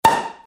короткие